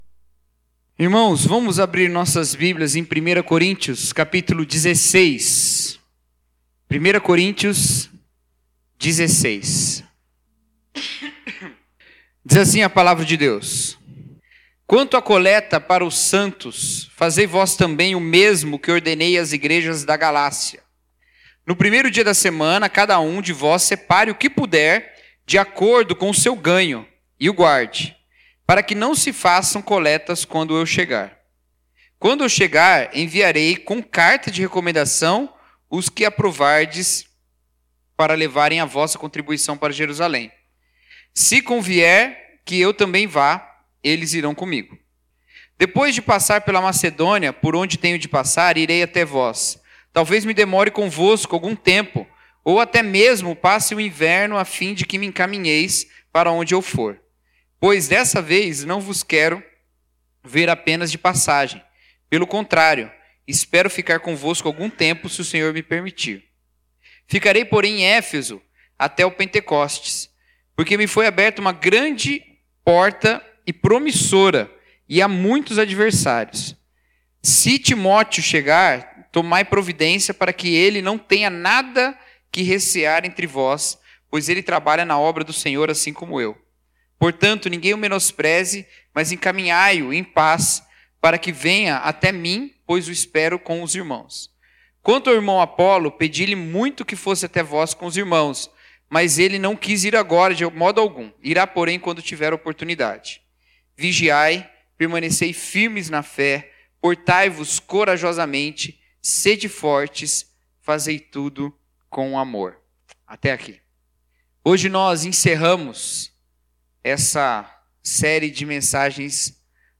Mensagem: A Missão da Igreja de Deus